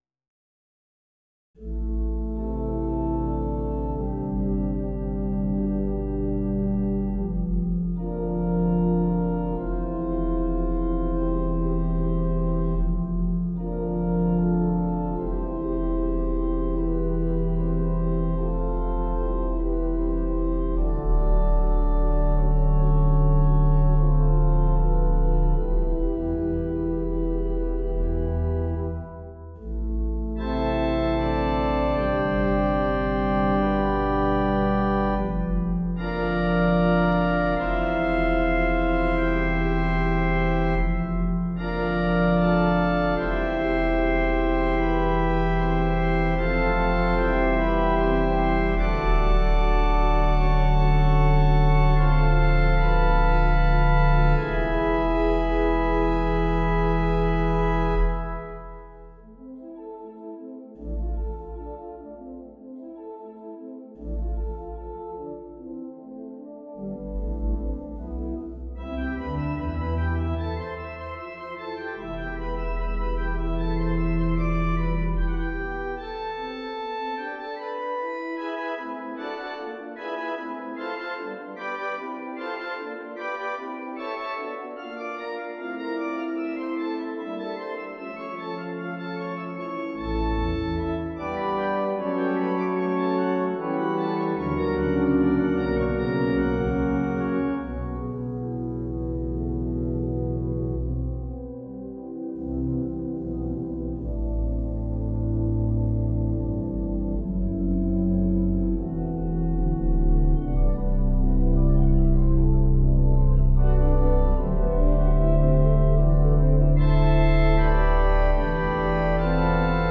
At the presto, the fugue employs previous gestures as episodes, and presses forward. A cadenza for pedal and then a gentle restatement of the opening finish the movement. The second movement uses open triads in the manuals as the pedal -- an 8' stop -- sings out over them. The last movement is filled with board, rhapsodic arpeggios -- rubato a piacere -- in a toccata
Sonata_for_Organ.mp3